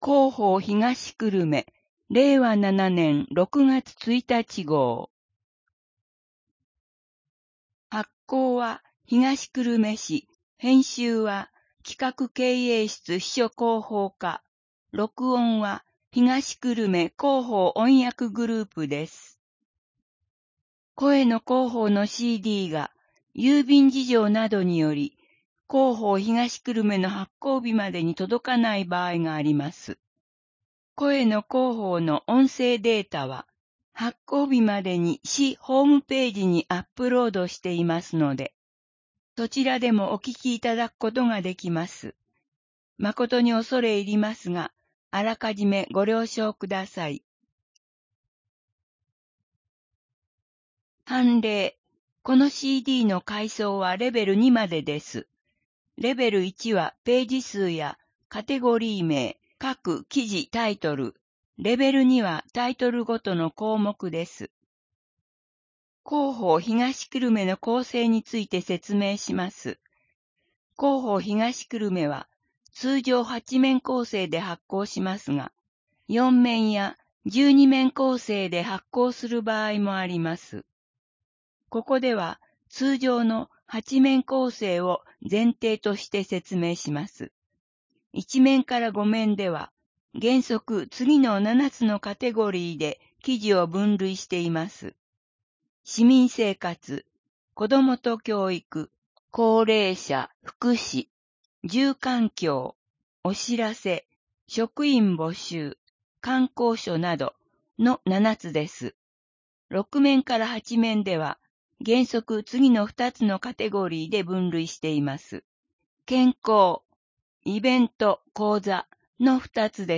声の広報（令和7年6月1日号）